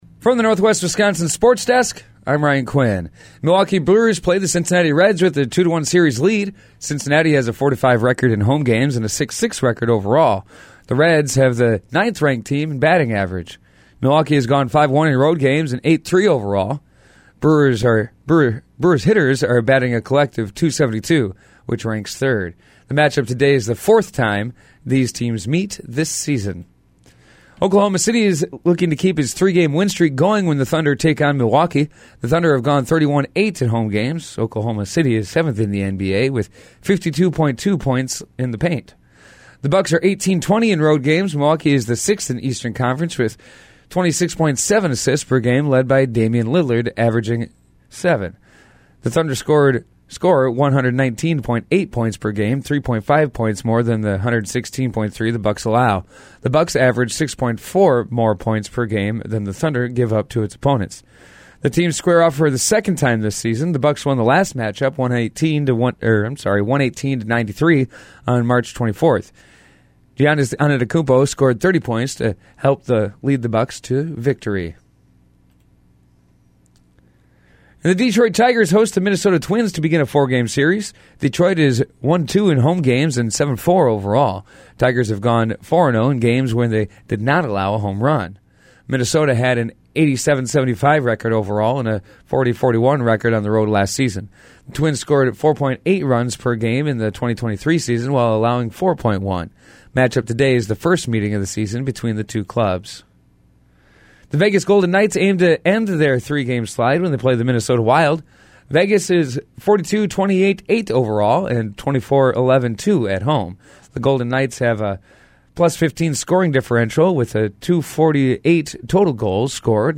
Today’s sportscast from the Northwest Wisconsin Sports Desk.